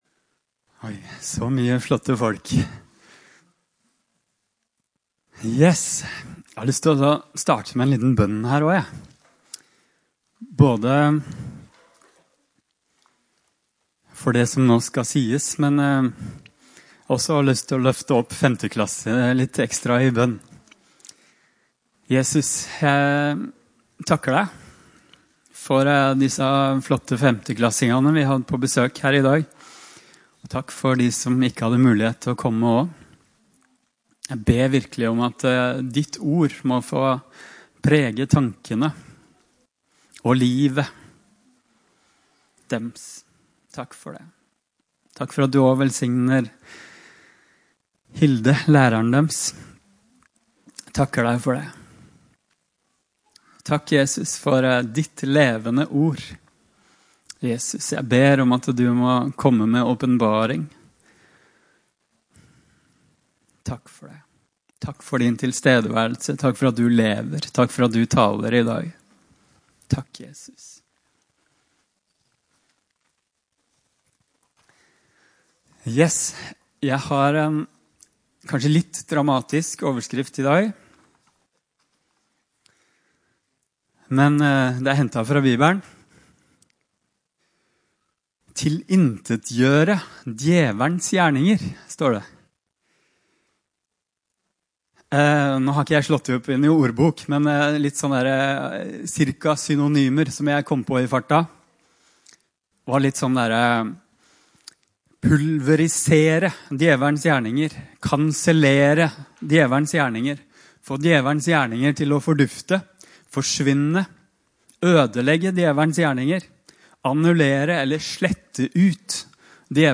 Opptak av tale
Tid: 14. september 2025 kl. 11 Sted: No12, Håkon den godes g. 12, Levanger